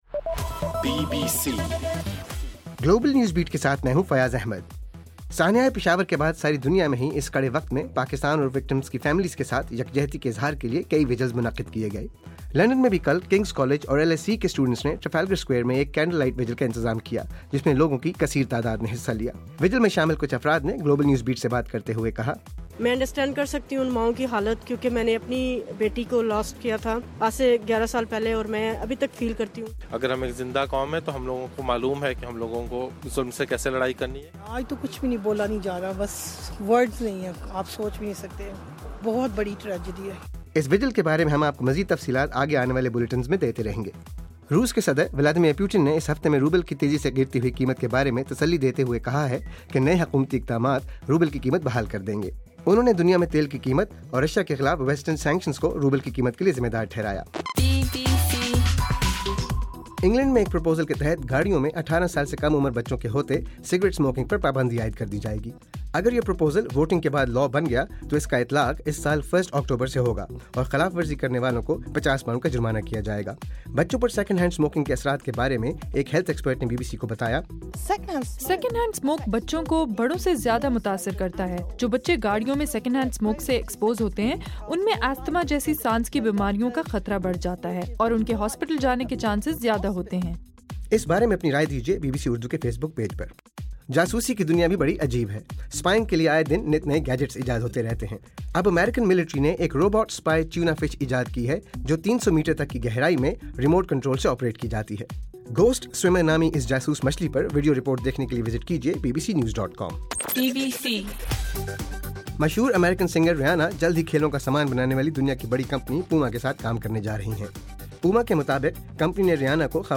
دسمبر 18: رات 10 بجے کا گلوبل نیوز بیٹ بُلیٹن